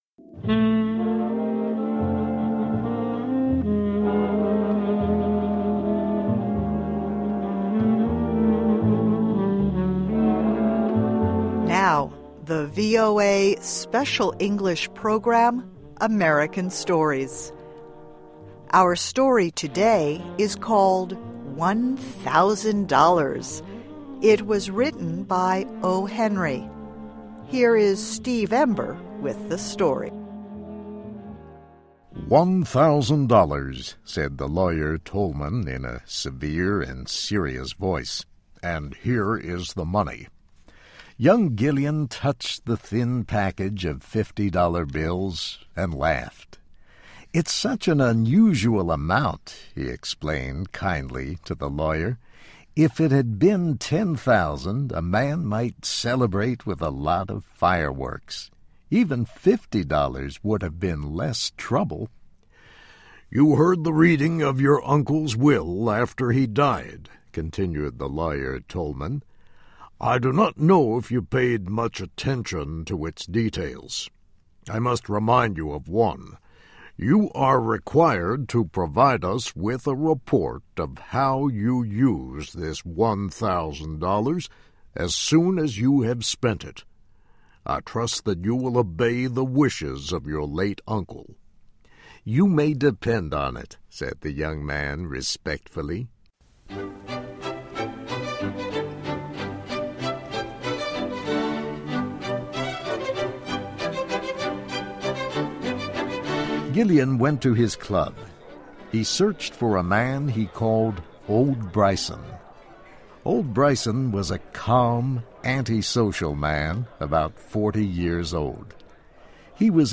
the VOA Special English program, AMERICAN STORIES. (MUSIC) Our story today is called "One Thousand Dollars." It was written by O. Henry.